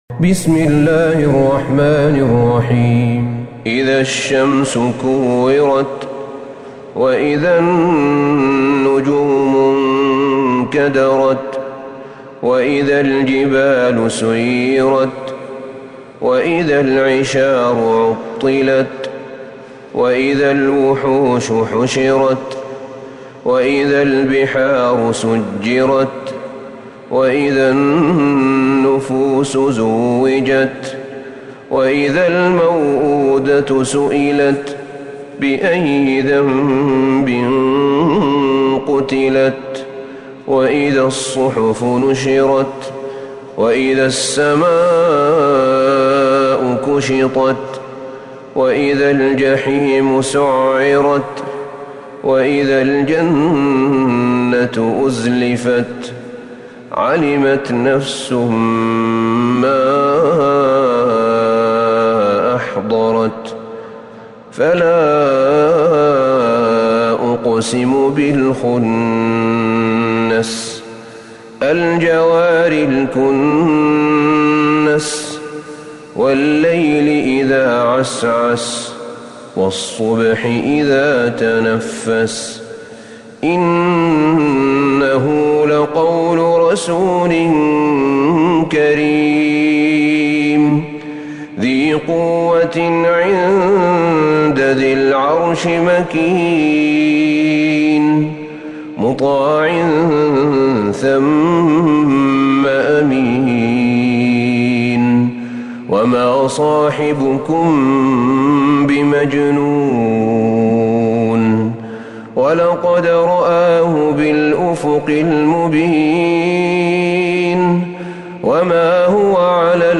من الحرم النبوي
تلاوات الحرمين